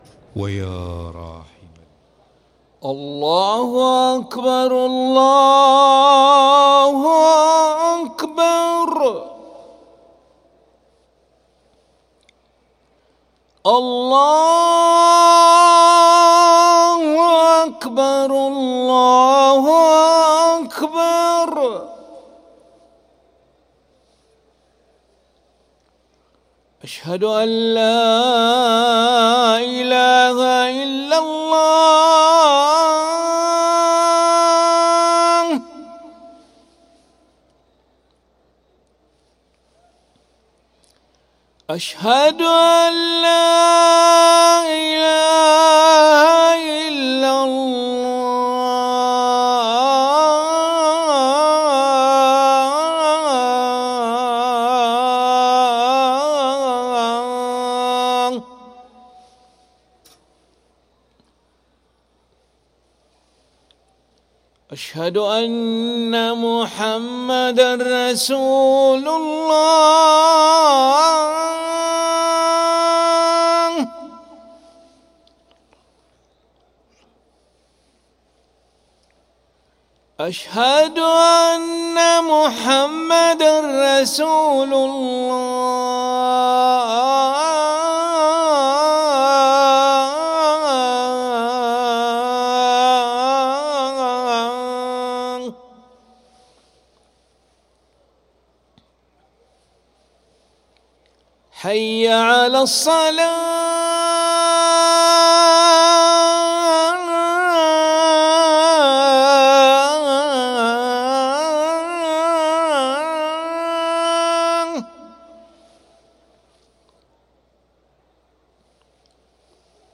أذان العشاء للمؤذن علي أحمد ملا الخميس 19 ذو القعدة 1444هـ > ١٤٤٤ 🕋 > ركن الأذان 🕋 > المزيد - تلاوات الحرمين